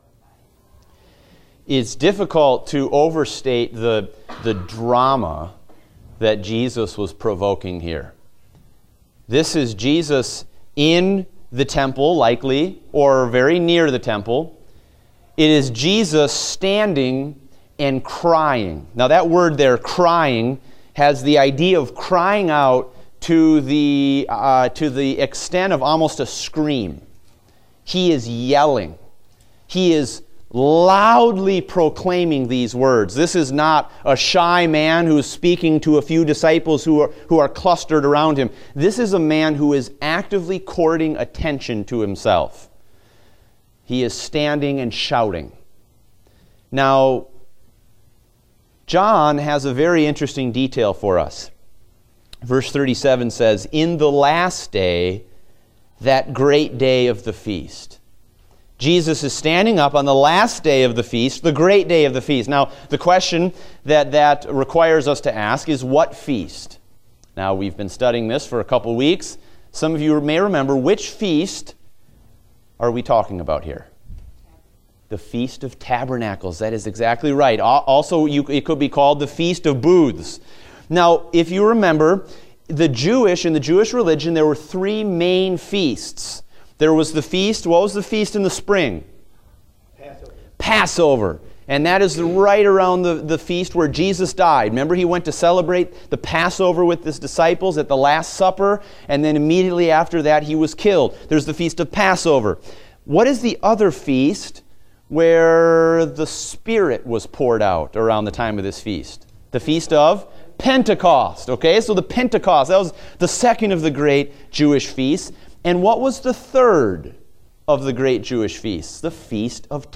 Date: September 18, 2016 (Adult Sunday School)